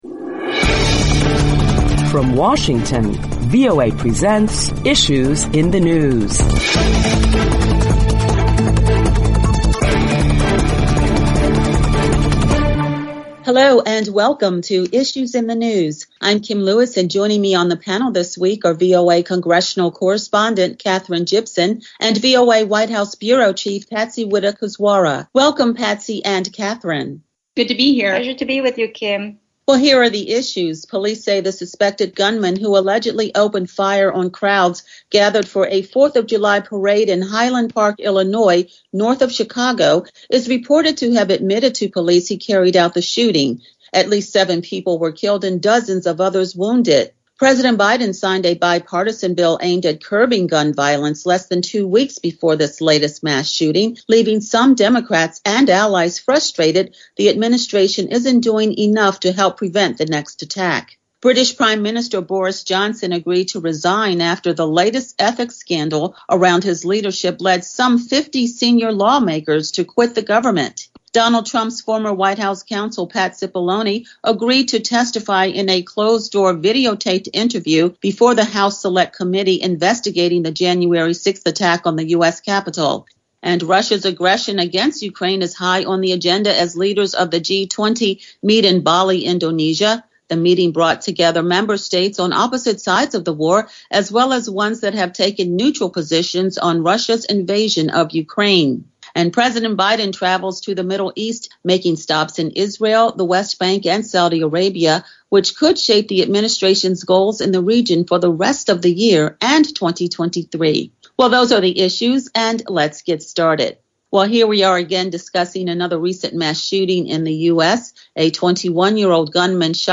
talks with VOA Correspondents